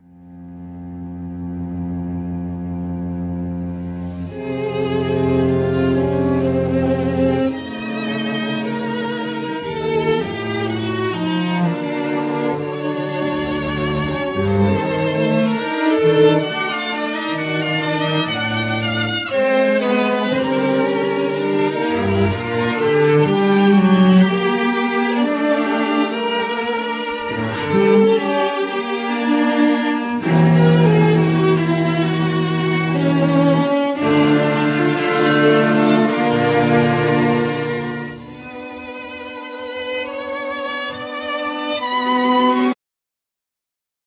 Violin
Bassoon
Piano
Double-Bass
Drums,Percussions
Clarinet
Strings Quartet
Organ